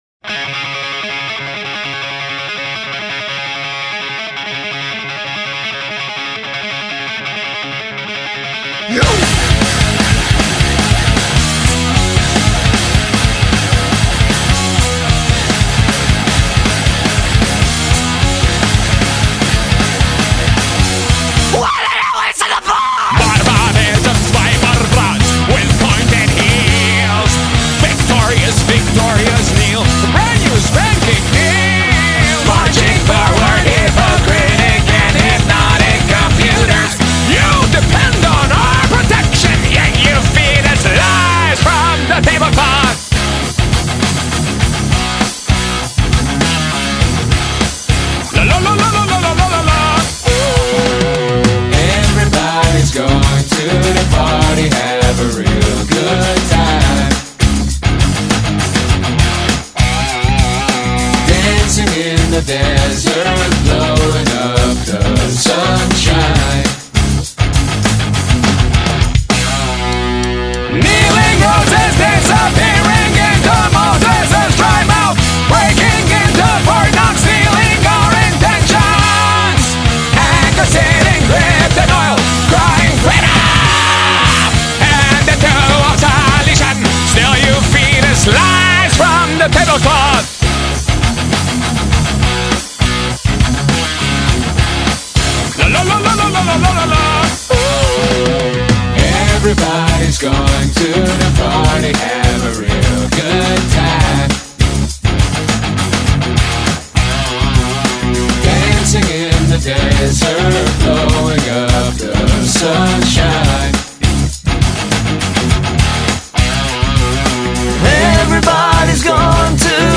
В альбоме есть бешеные переломы ритма и разнообразные стили,
от трэша до панка